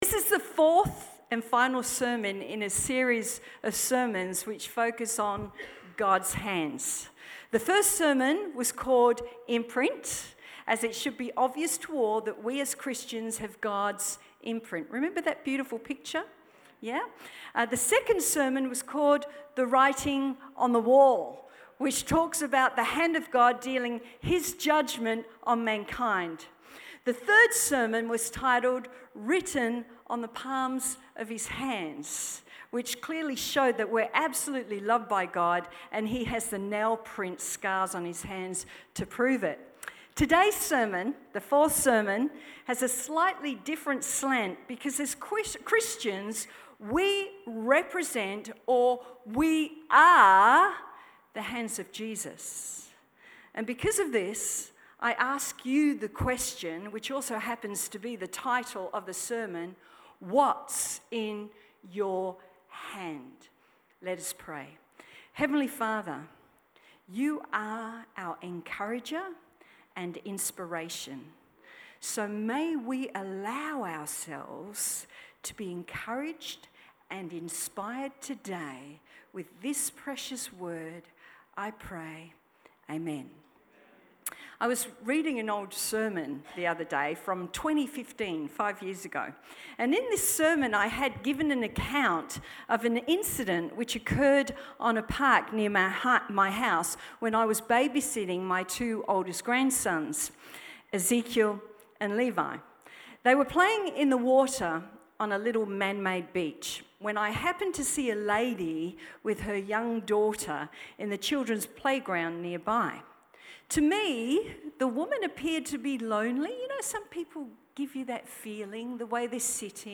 As Christians, we represent the hands of Jesus and for this reason, this sermon asks the question, What is in Your Hand?